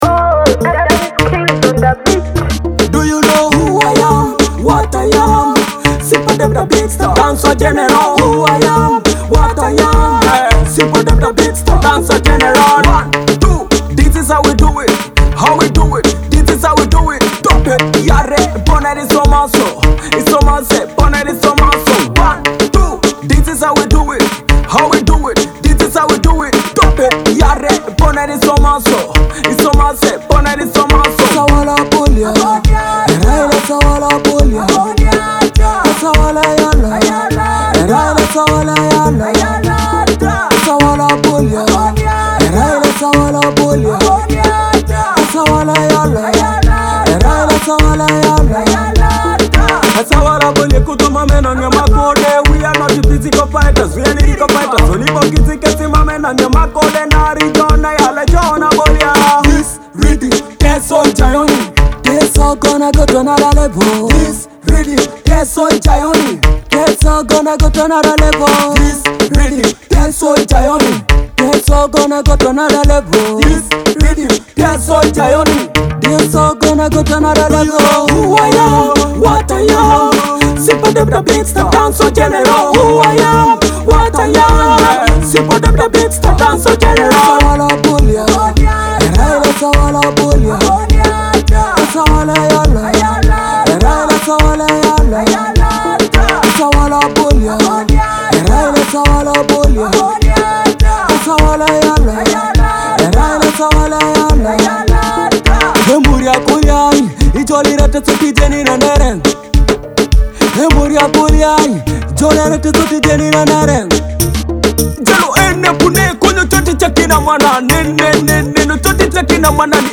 a reggae tone hit celebrating life, love, and reflection.
bringing a modern Afrobeat twist